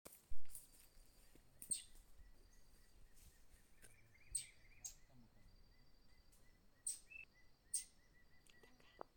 Rufous Gnateater (Conopophaga lineata)
Life Stage: Adult
Location or protected area: Reserva Natural Osununú
Condition: Wild
Certainty: Observed, Recorded vocal